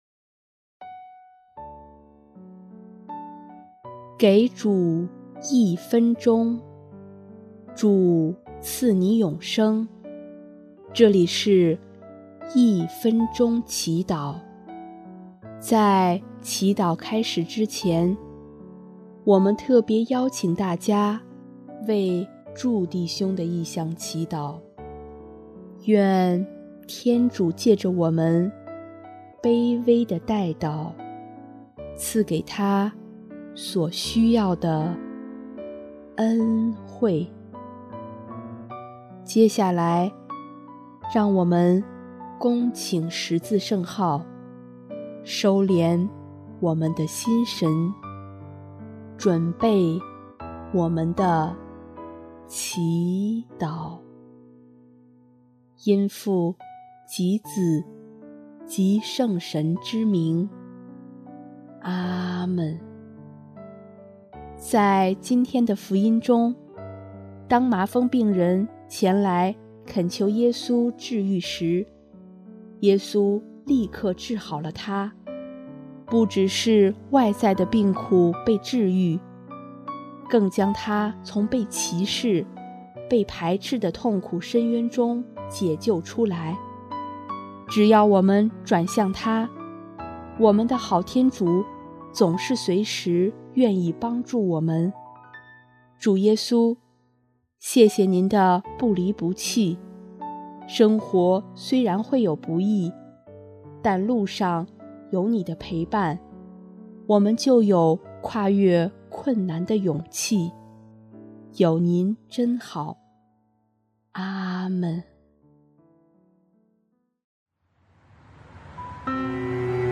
【一分钟祈祷】|1月16日 主，有你真好